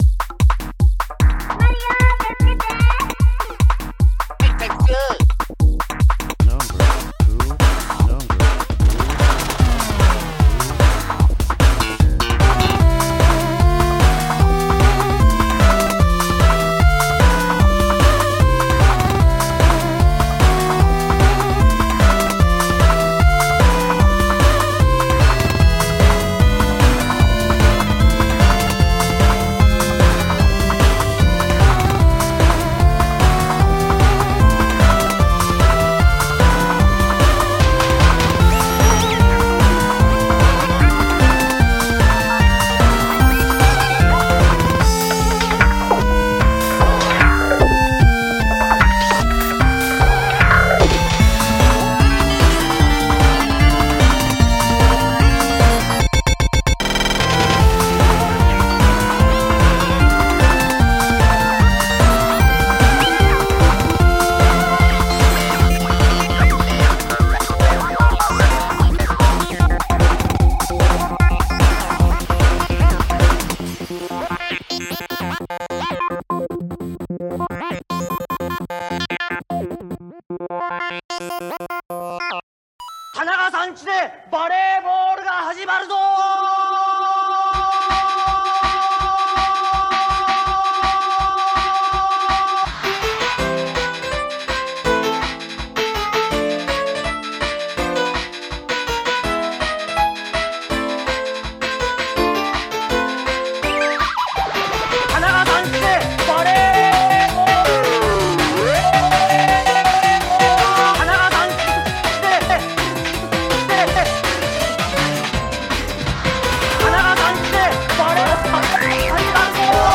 ２つのゲームミュージックを
Mac speak text
YMCK Magical 8bit Plug
Atari 520ST speech synthesizer